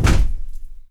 FEETS 3   -L.wav